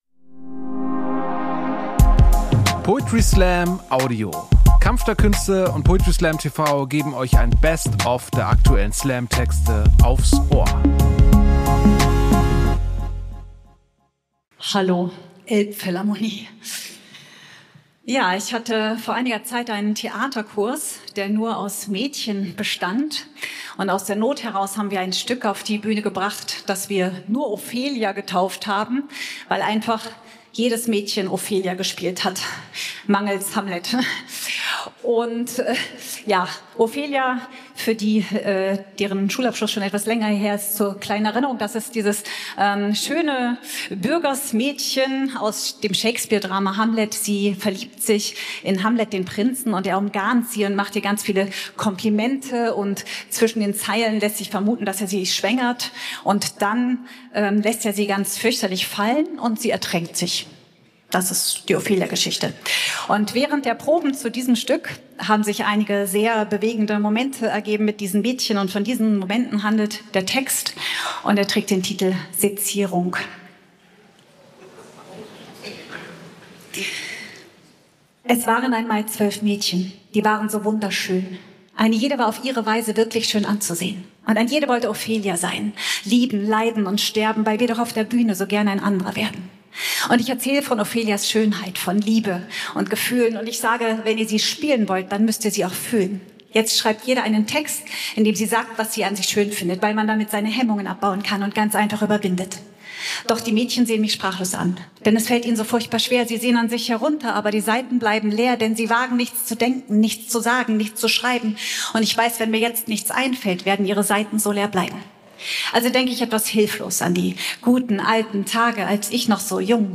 Stage: Elbphilharmonie, Hamburg